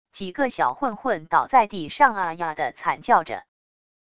几个小混混倒在地上啊啊的惨叫着wav音频